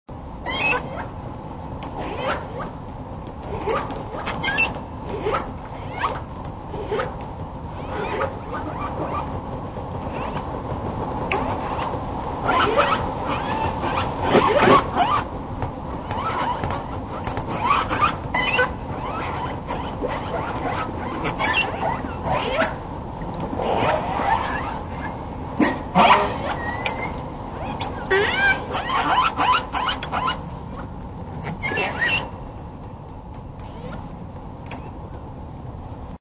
Orca Killer Whale